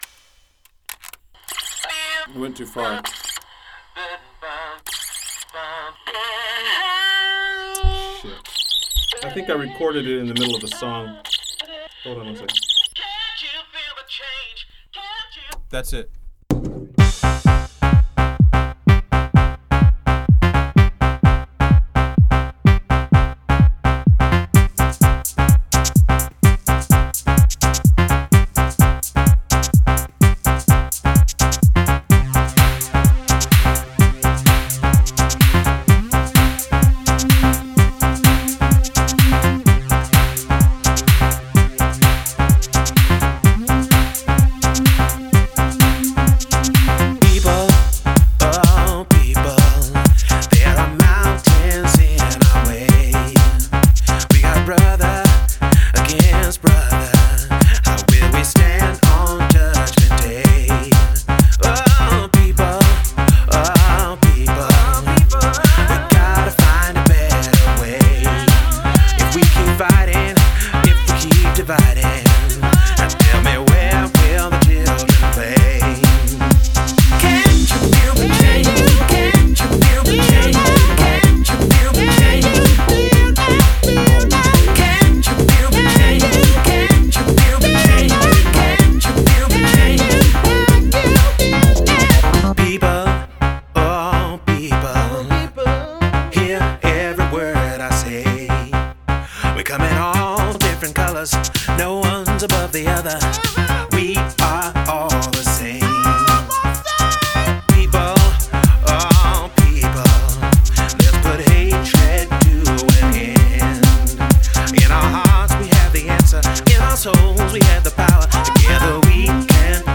Dance Данс музыка Dance music